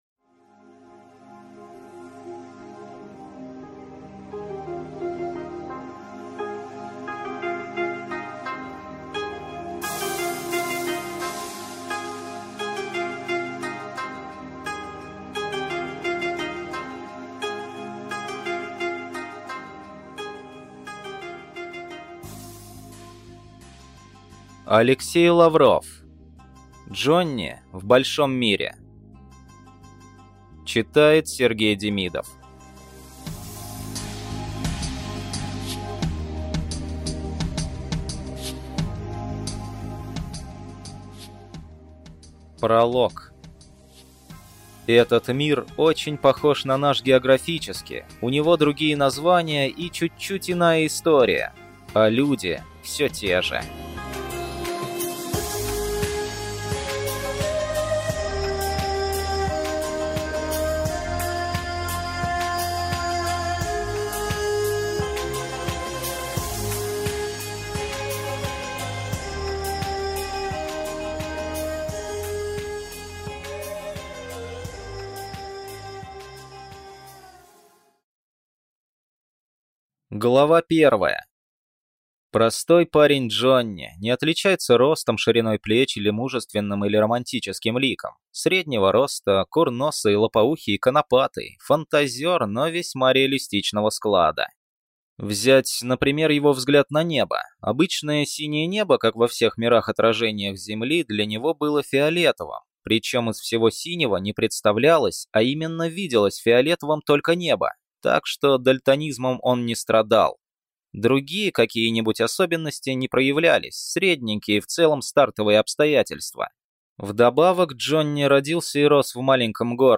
Аудиокнига Джонни в большом мире | Библиотека аудиокниг